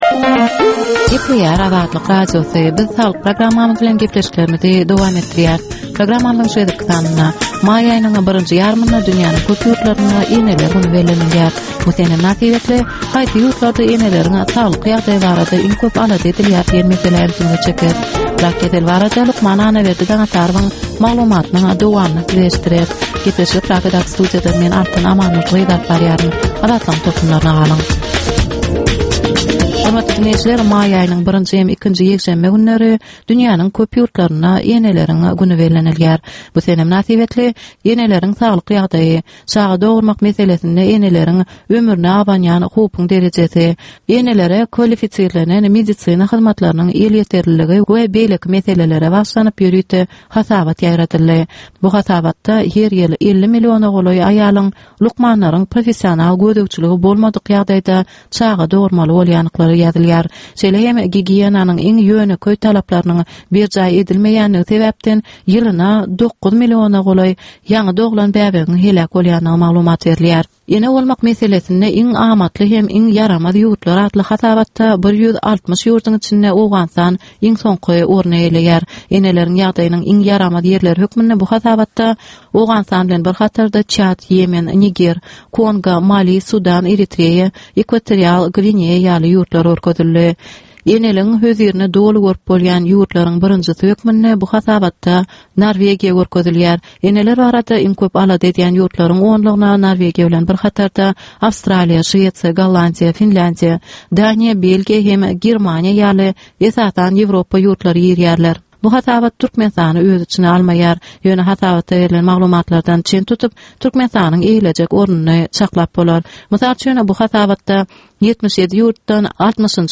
Ynsan saglygyny gorap saklamak bilen baglanyşykly maglumatlar, täzelikler, wakalar, meseleler, problemalar we çözgütler barada ýörite gepleşik.